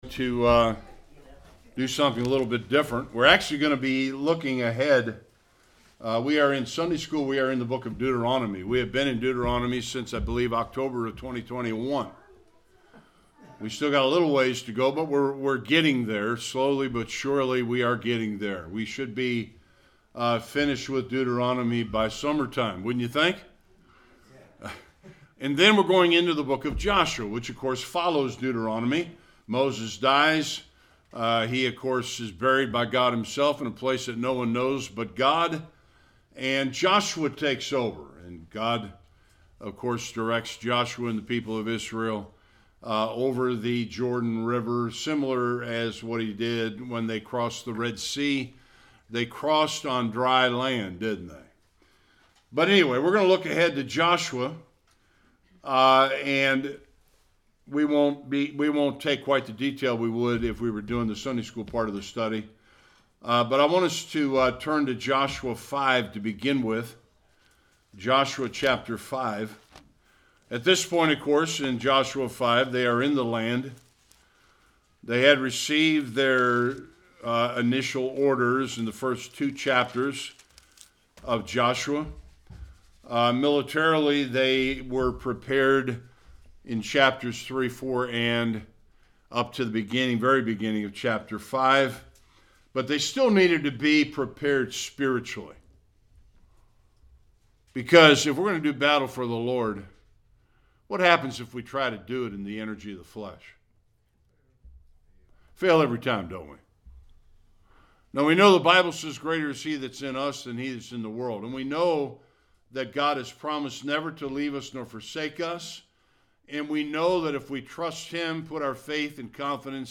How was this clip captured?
5-9 Service Type: Sunday Worship 4 experiences brought to Joshua and Israel that centered around a token or symbol.